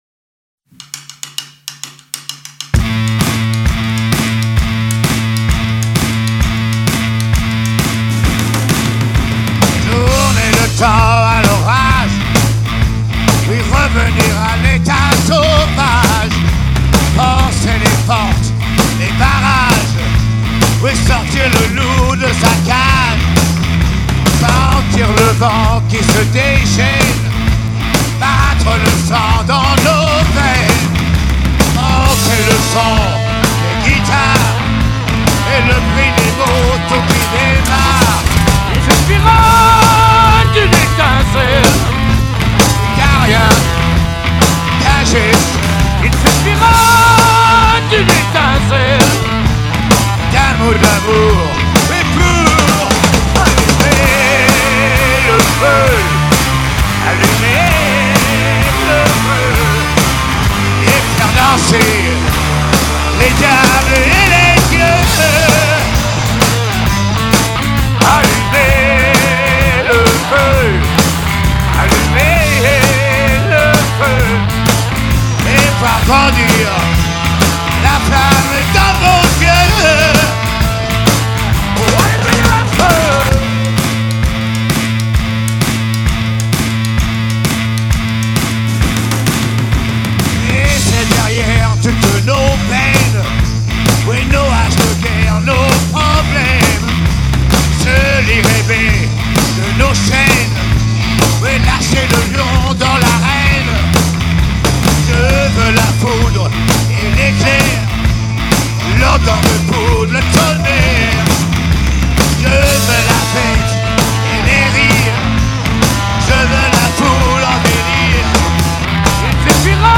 Instrumentale
acapella .